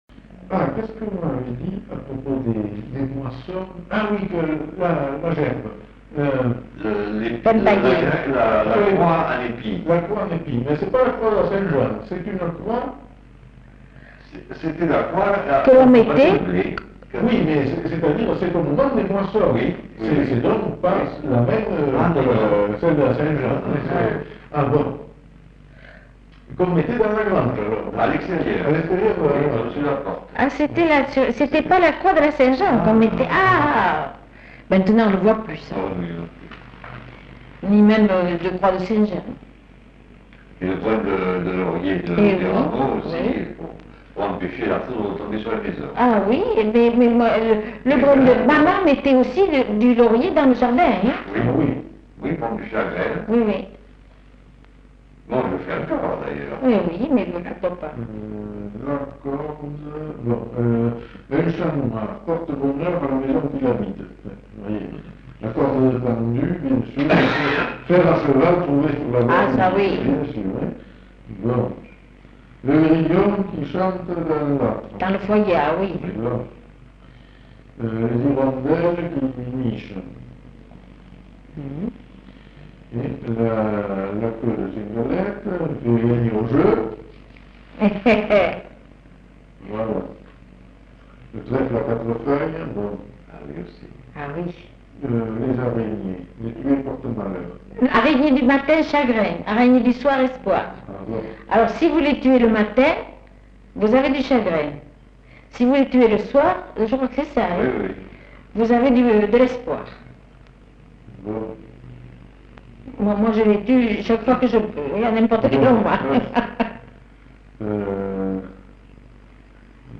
Genre : témoignage thématique
[enquêtes sonores]